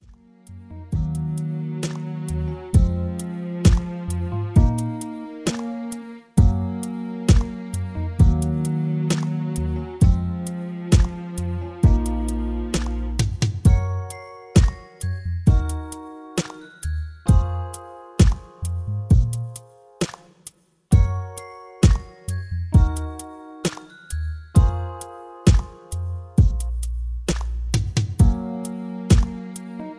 Smooth R&B Balad